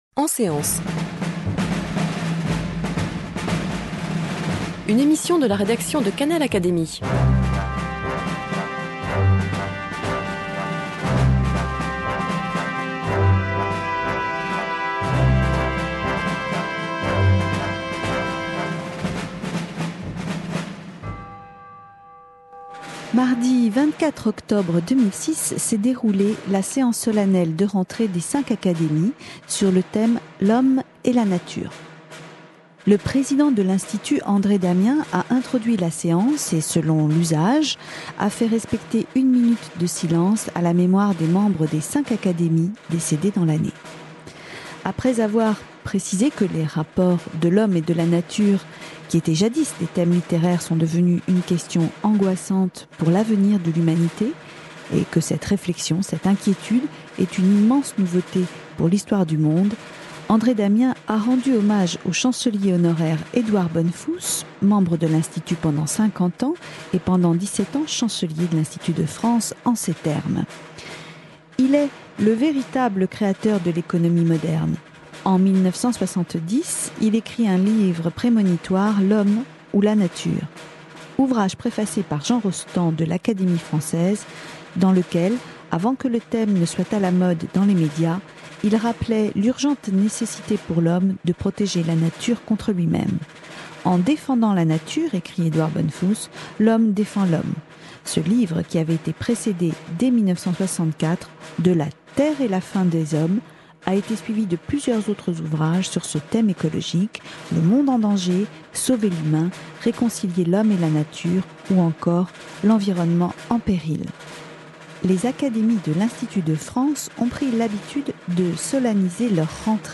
Séance solennelle de rentrée des cinq académies du 24 octobre 2006
Le président a introduit la séance et selon l'usage, a fait respecter une minute de silence à la mémoire des membres des cinq académies décédés dans l'année.